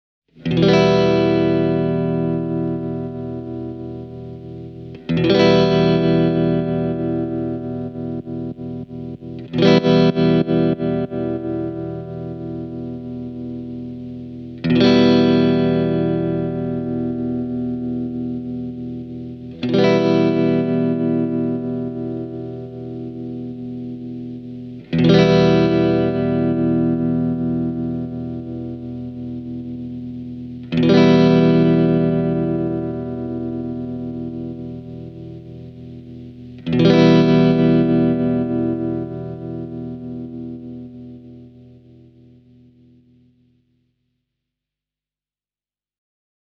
Tämä klippi esittelee Gurus Sinusoidin tremoloefektiä eri säädöillä. Testikitarana toiminut Fender Stratocaster oli kytketty suoraan pedaaliin:
gurus-amps-sinusoid-e28093-optical-tremolo.mp3